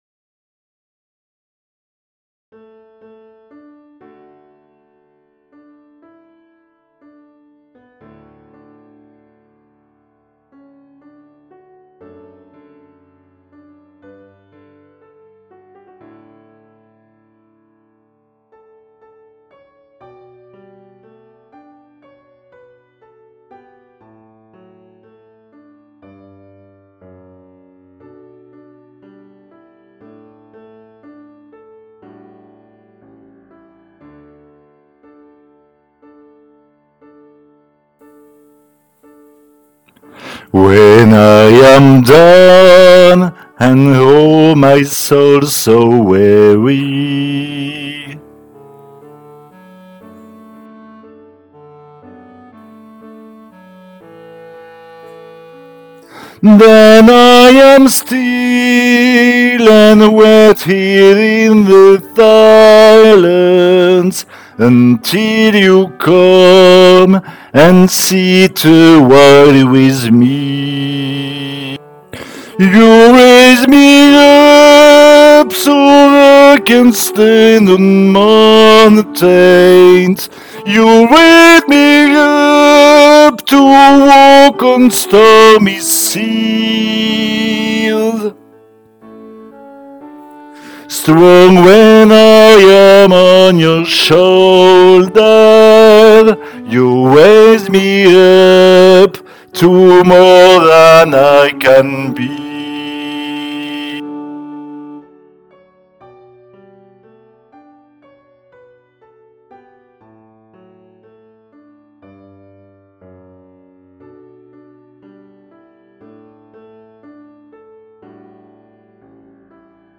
voix chantée